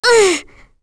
Kirze-Vox_Attack1.wav